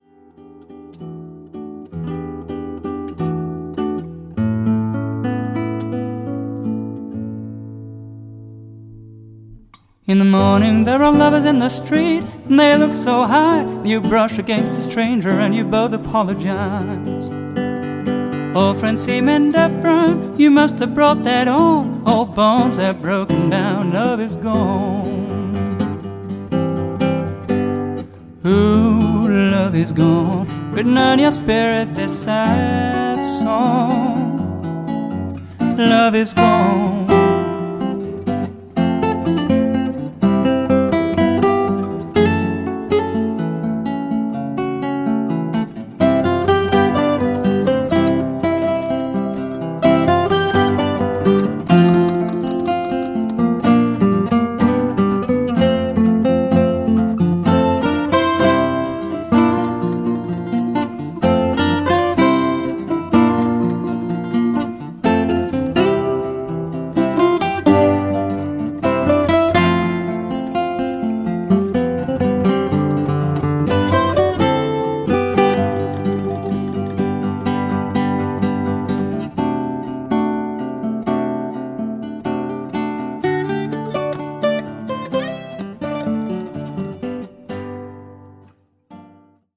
voc